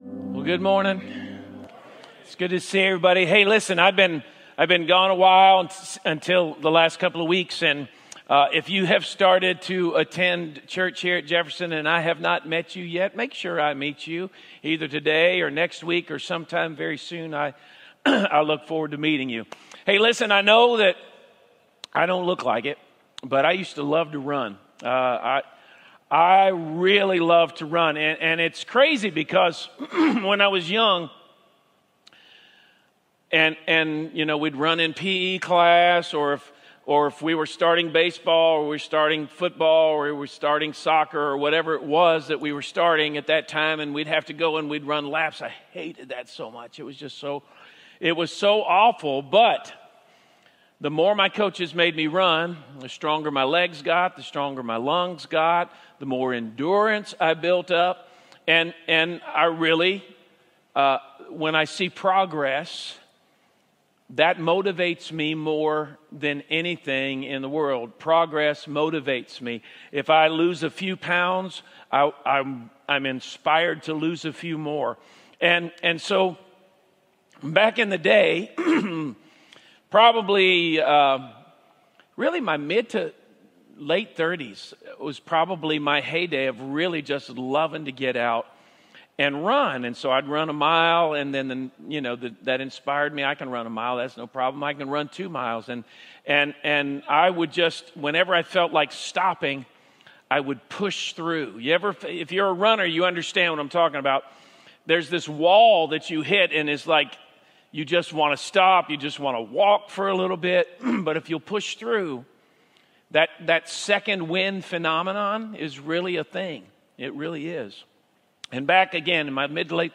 Sermons | Jefferson Christian Church